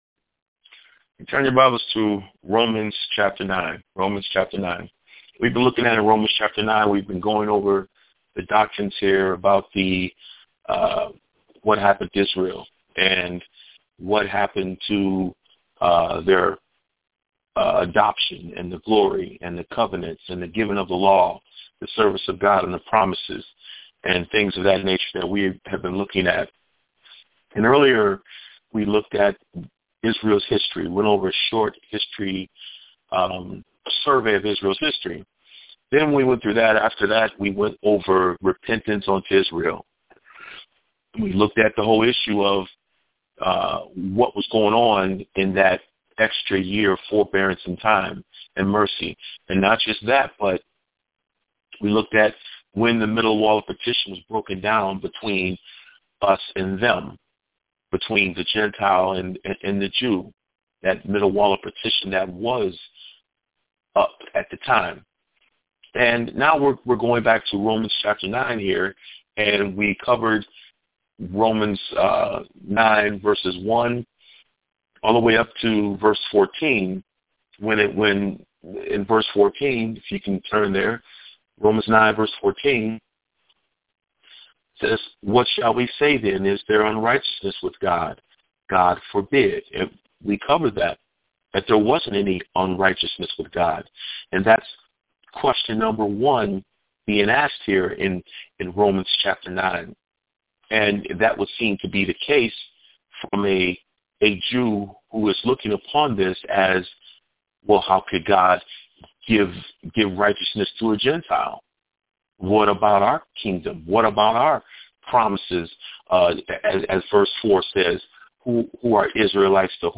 Weekly Phone Bible Studies